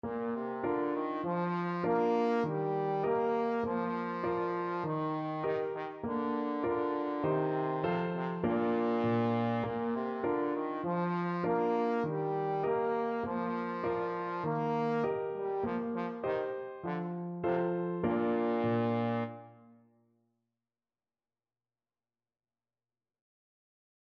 4/4 (View more 4/4 Music)
Moderato
Bb3-Bb4